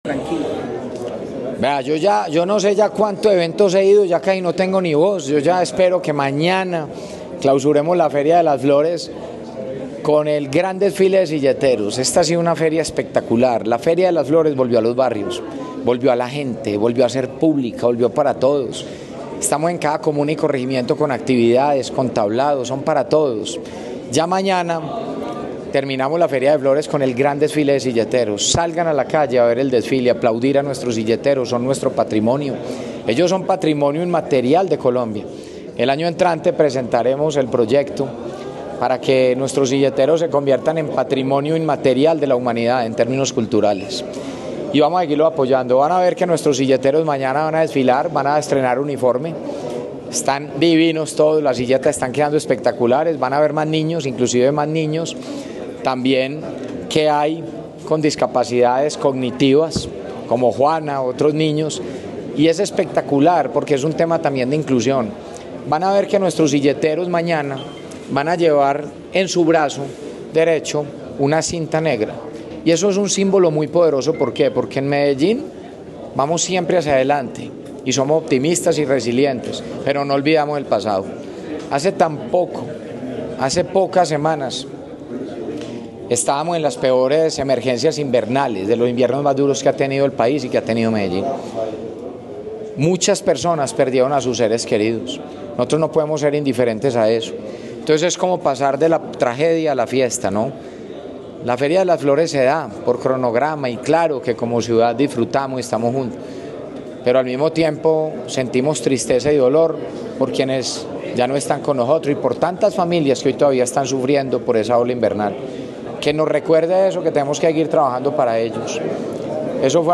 Declaraciones-alcalde-de-Medellin-Federico-Gutierrez-Zuluaga.mp3